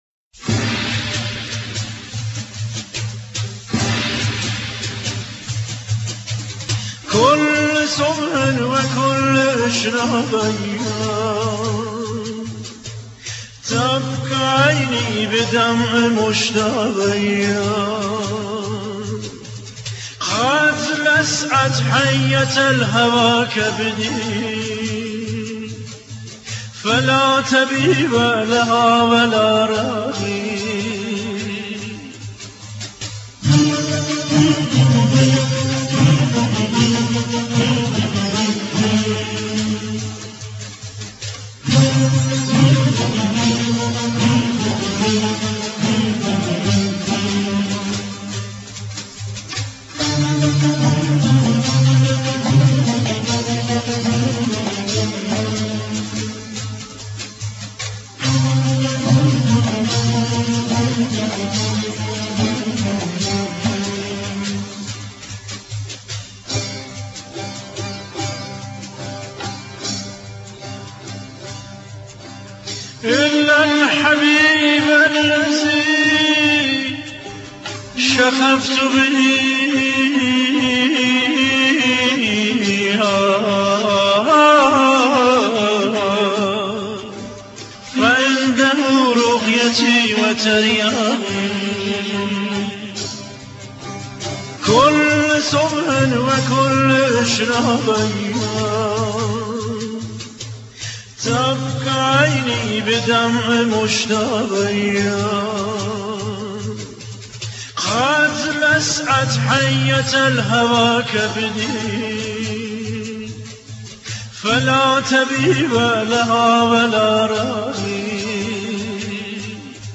تيتراژ سريال